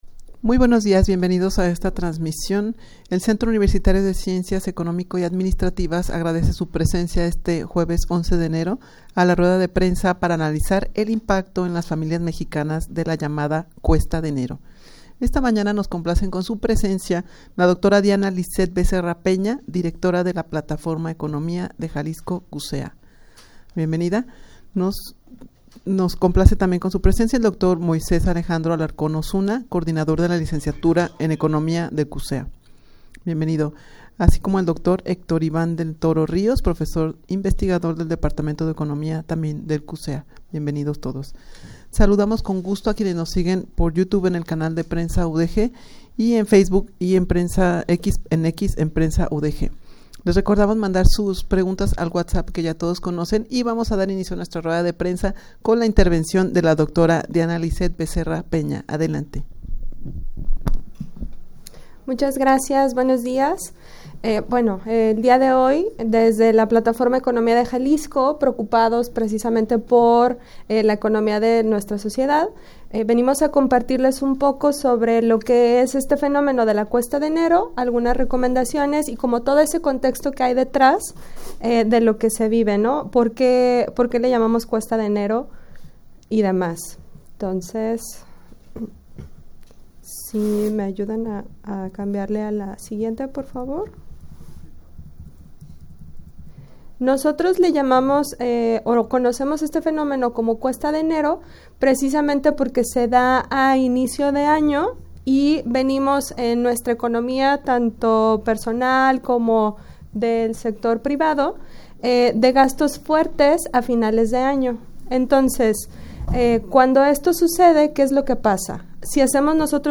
Audio de la Rueda de Prensa
rueda-de-prensa-para-analizar-el-impacto-en-las-familias-mexicanas-de-la-llamada-cuesta-de-enero.mp3